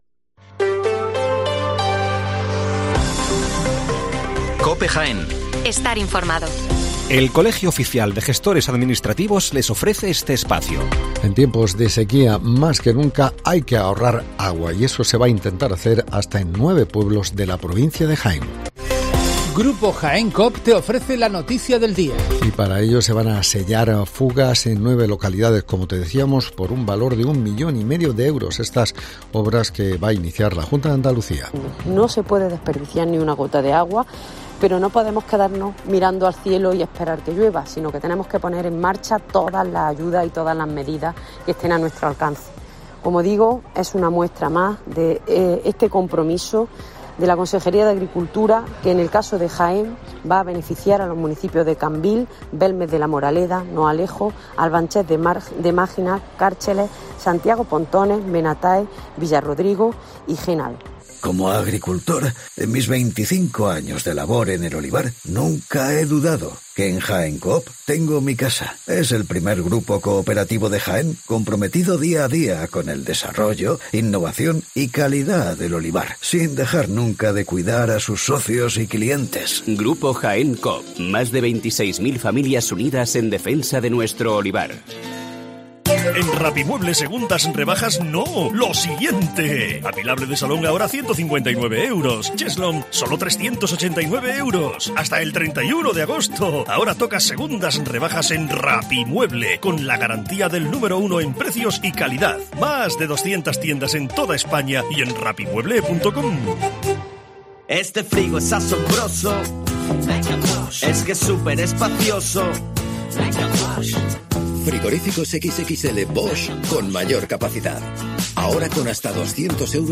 Las noticias locales de las 7'55 horas del 17 de agosto de 2023